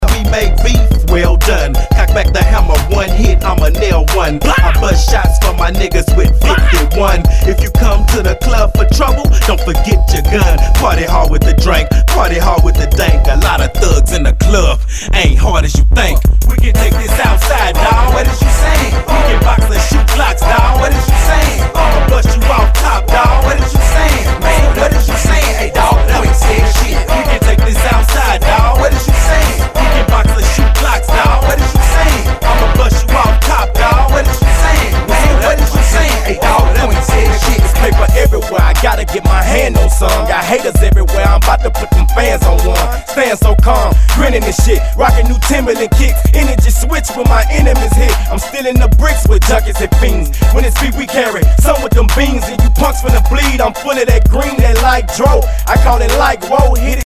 HIPHOP/R&B
スレ、キズ少々あり（ストレスに感じない程度のノイズが入ることも有り）